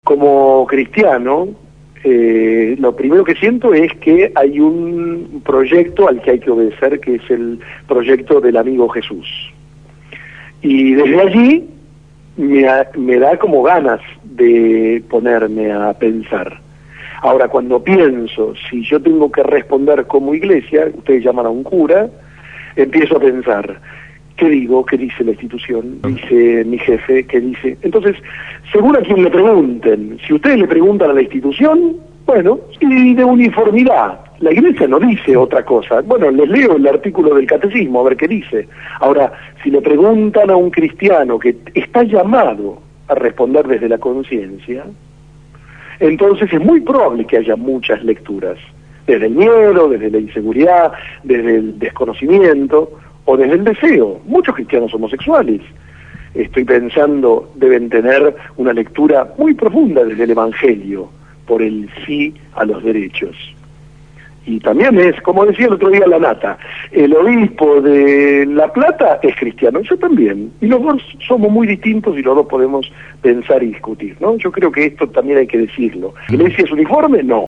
Sacerdote por la opción por los pobres se pronunció en la Gráfica